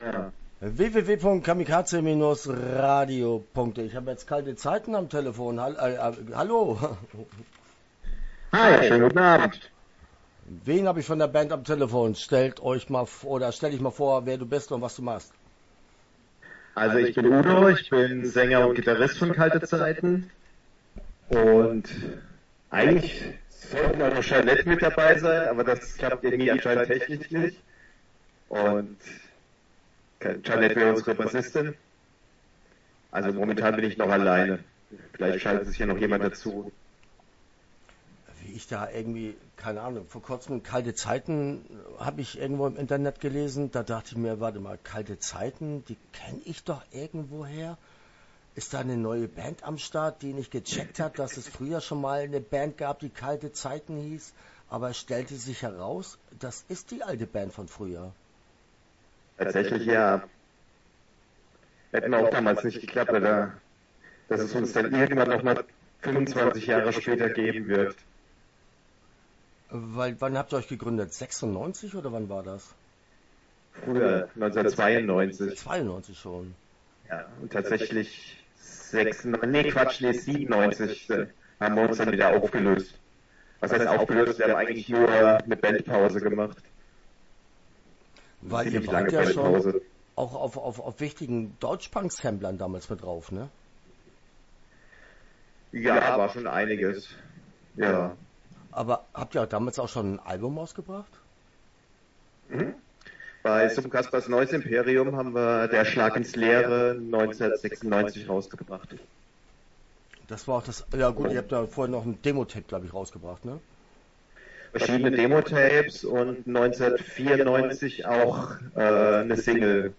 Kalte Zeiten - Interview Teil 1 (10:52)